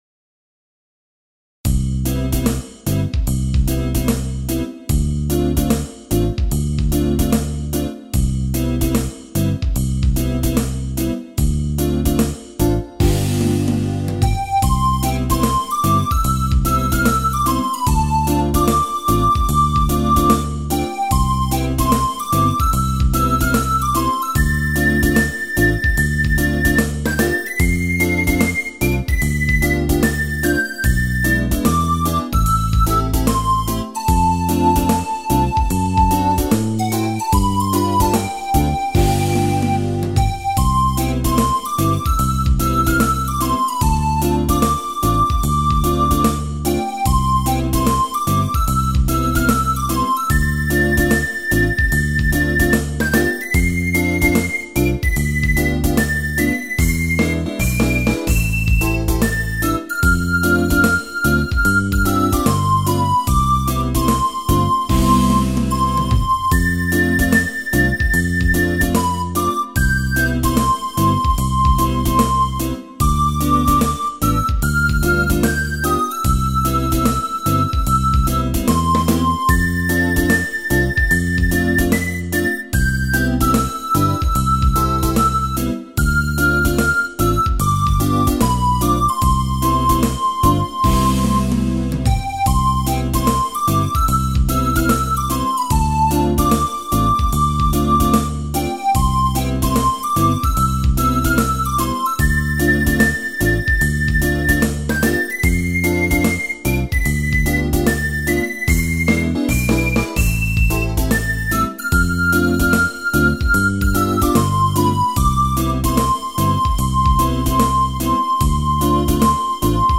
BGM
インストゥルメンタルスローテンポロング